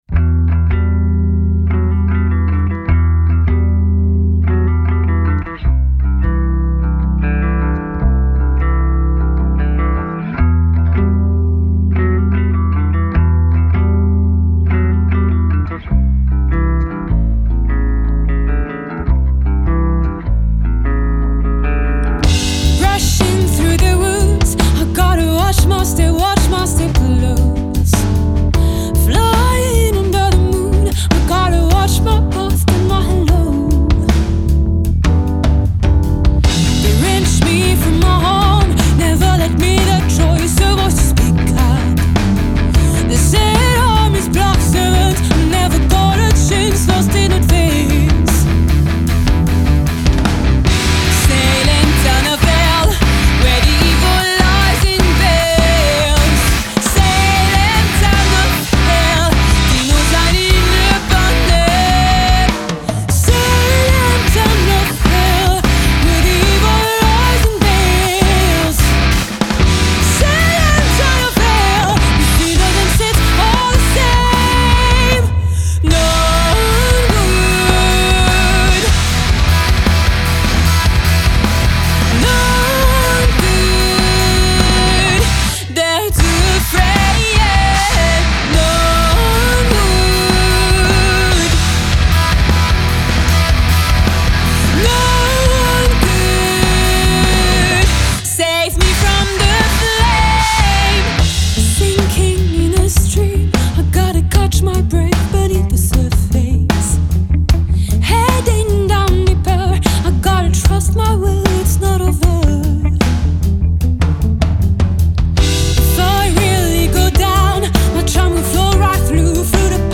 Rock Prog moderne